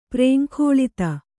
♪ prēŋkhōḷita